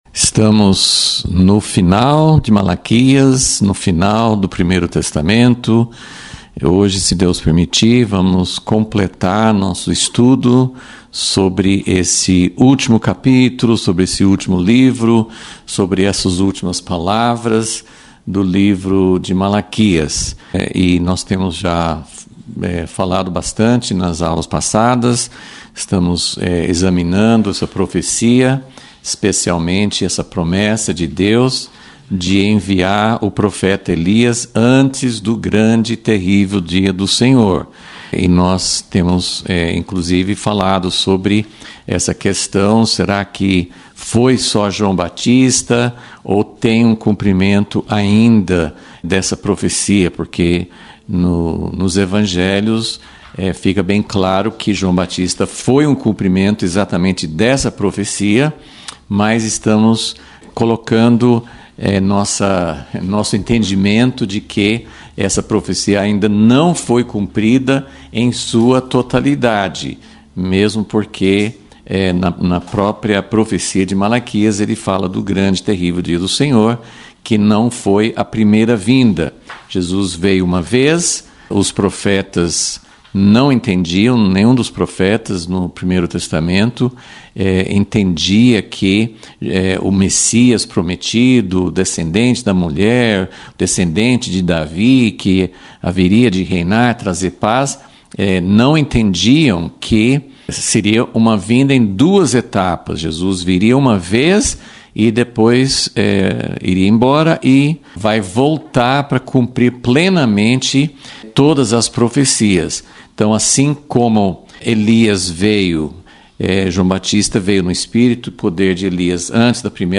Aula 30 – Vol. 37 – Haverá mesmo uma voz profética clara no tempo do fim?